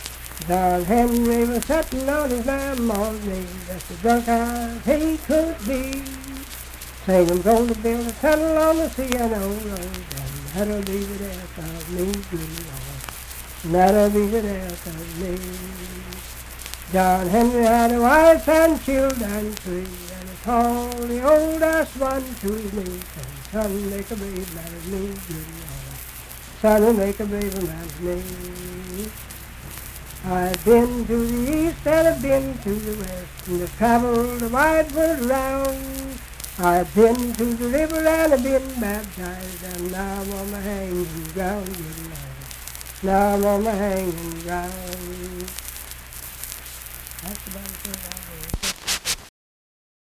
Unaccompanied vocal music
Verse-refrain 3(4-5w/R).
Performed in Ivydale, Clay County, WV.
Voice (sung)